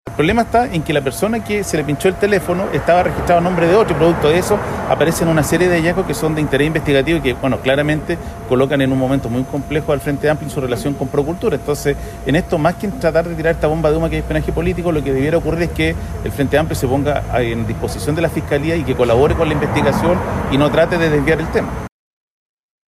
De hecho, el senador Pedro Araya (PPD) descartó que se trate de “espionaje político” y llamó a no desviar el foco del debate, instando a colaborar con la investigación.